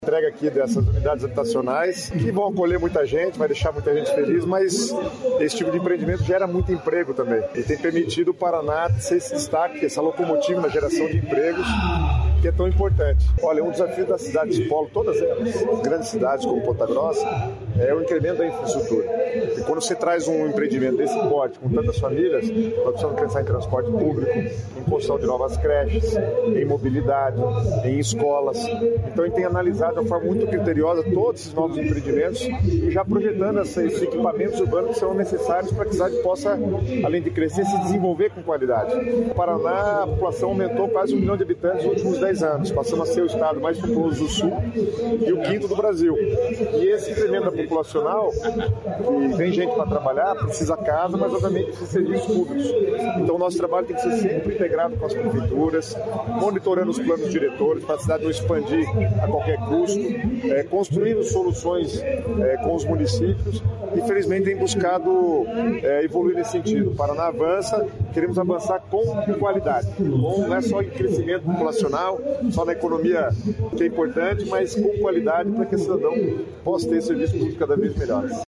Sonora do secretário estadual das Cidades, Guto Silva, sobre bairro planejado de Ponta Grossa